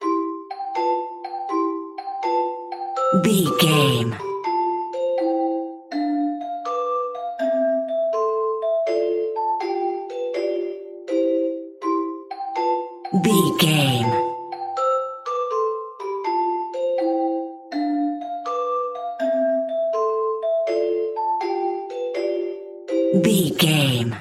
Ionian/Major
Slow
nursery rhymes
childrens music